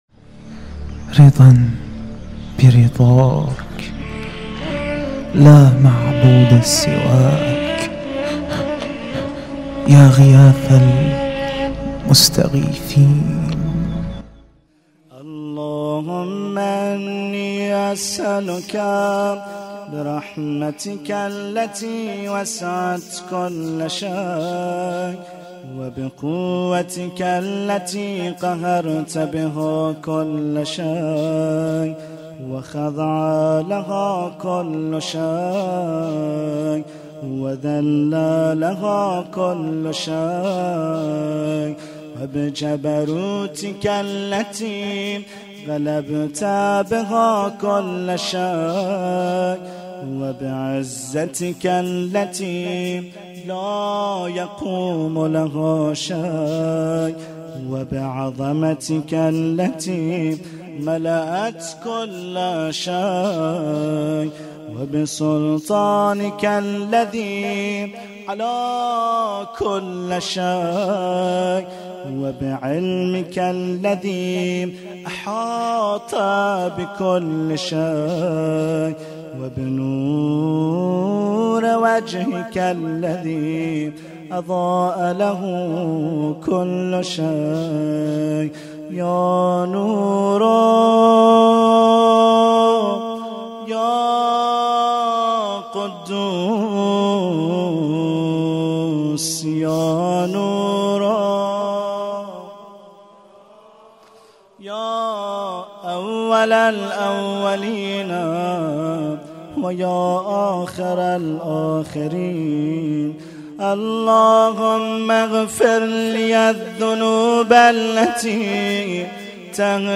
قرائت دعای کمیل
1437 هجری قمری- هیأت علی اکبر بحرین